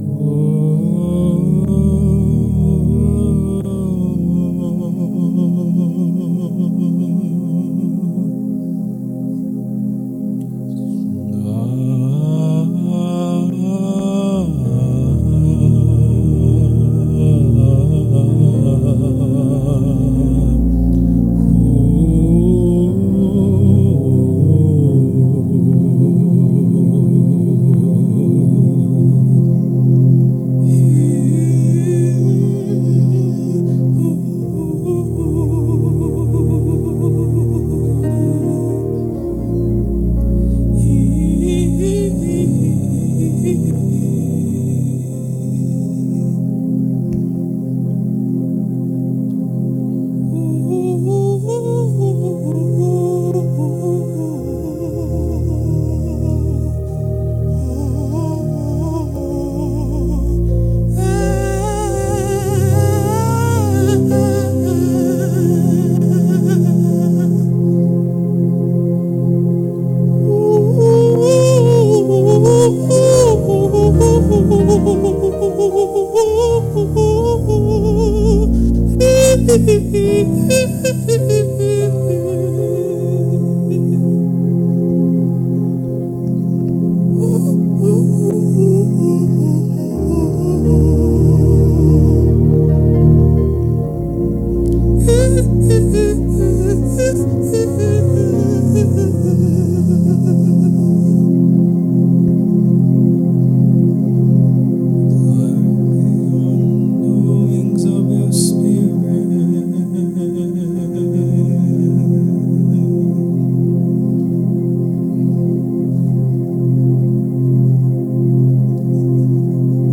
African Gospel Music